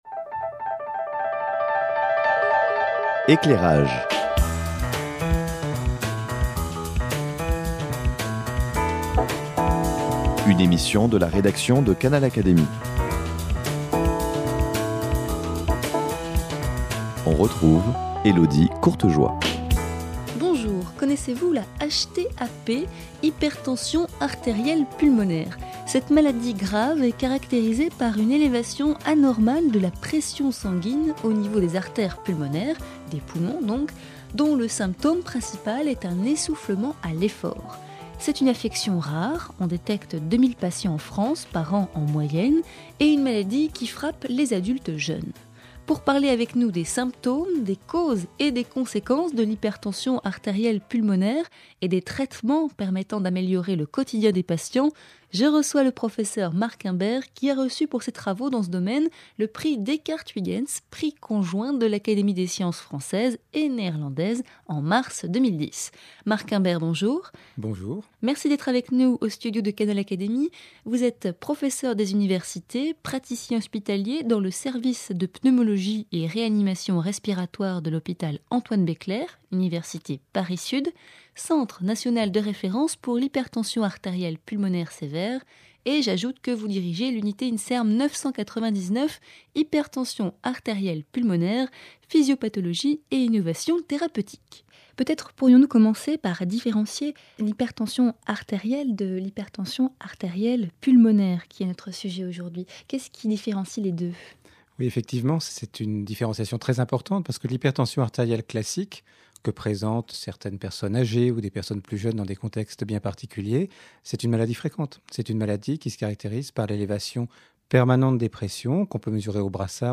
revient dans cette interview sur les symptômes